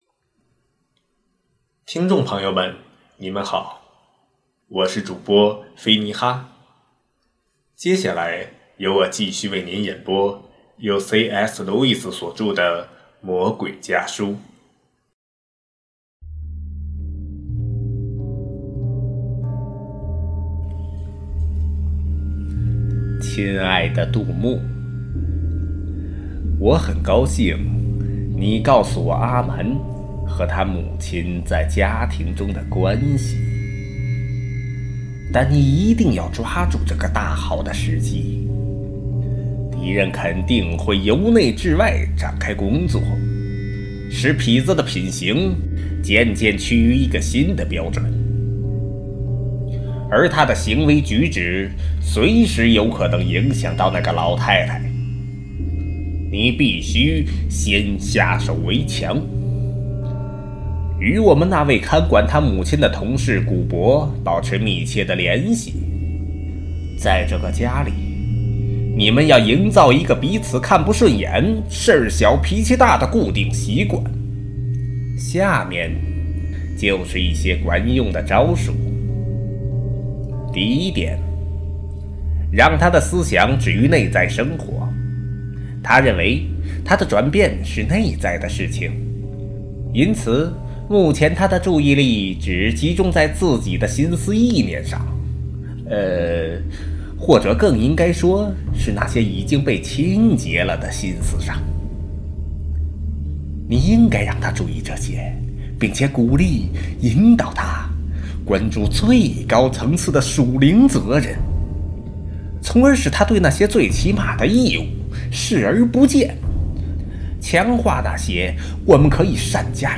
首页 > 有声书 | 灵性生活 | 魔鬼家书 > 魔鬼家书：第三封书信